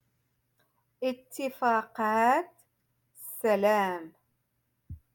Moroccan Dialect- Rotation Five-Lesson sixty Three